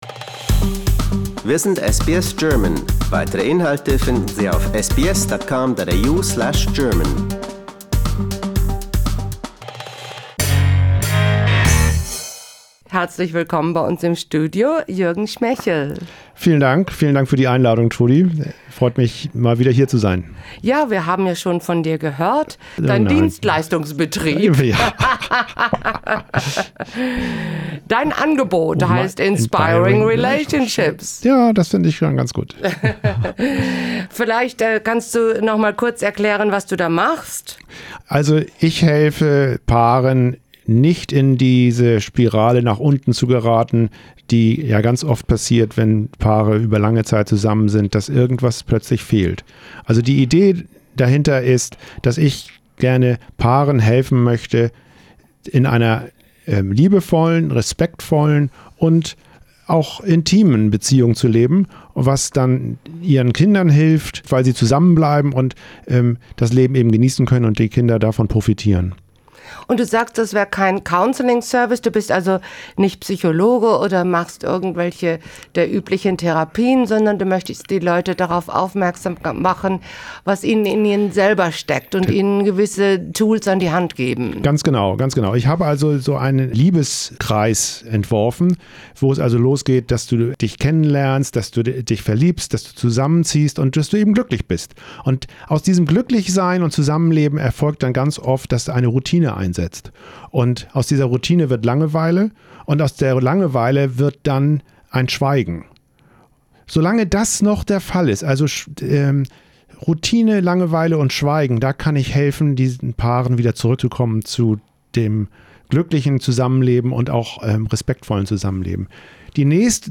We talk about how to make family holidays so everyone can relax. This first part of the interview is about how to deal with relationship problems in general, in the second part we come to the Christmas part, in which we talk about how we can get through the holidays well.